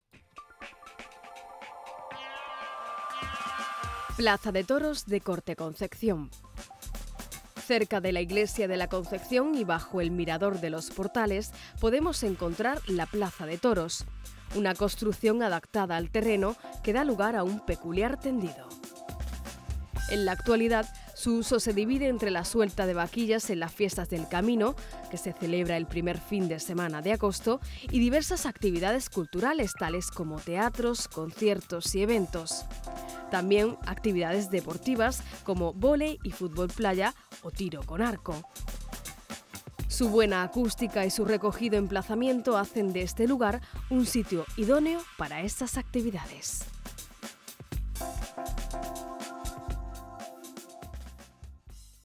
AUDIOGUÍA